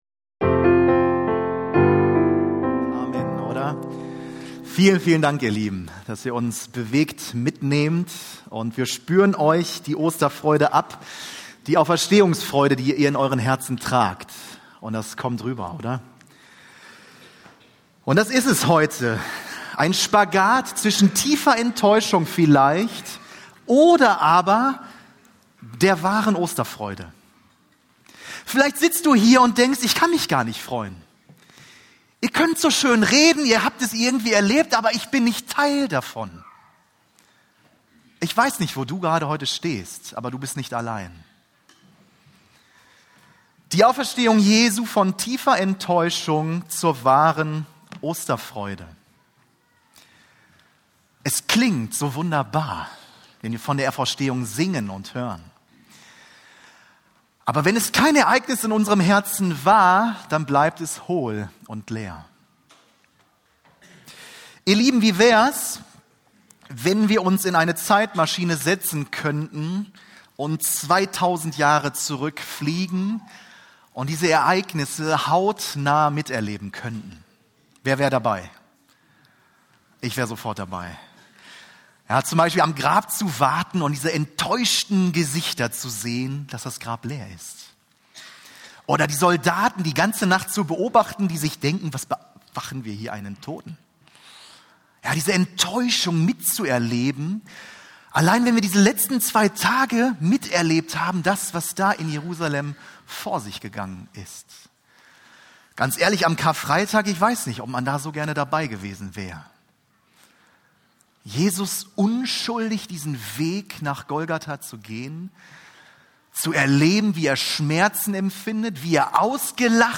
Ostergottesdienst